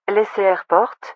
ATIS Sounds